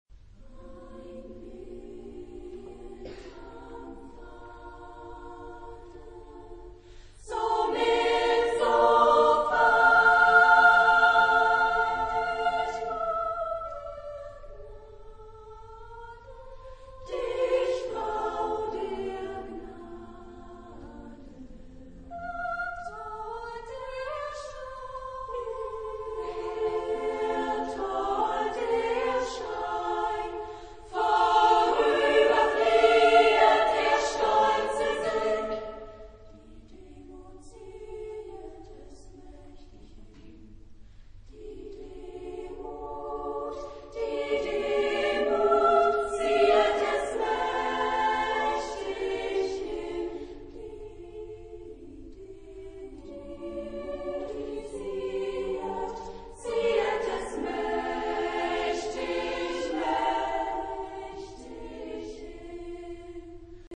Genre-Style-Form: Choir ; Poetical song
Mood of the piece: gentle ; expressive ; andante
Type of Choir: SSAA  (4 women voices )
Tonality: F major
sung by Acerva Oberspreewald-Lausitz
Discographic ref. : 7. Deutscher Chorwettbewerb 2006 Kiel